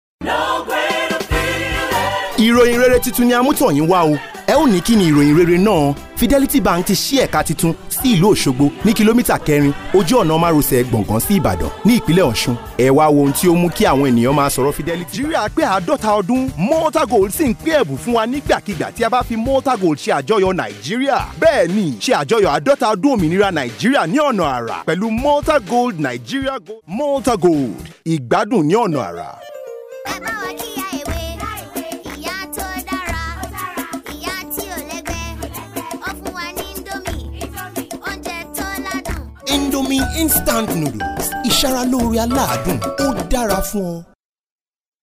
Sprechprobe: Sonstiges (Muttersprache):
Clients from around the world choose me for their voice over projects because of the warmth, realness and authenticity i bring to each project. I work from my professional grade studio doing commercials, internet audio, narrations, documentaries, E-learning modules and so many other forms of voice over work.
I speak an unaccented English and can do an African accent perfectly; i also speak Hausa and Yoruba languages fluently.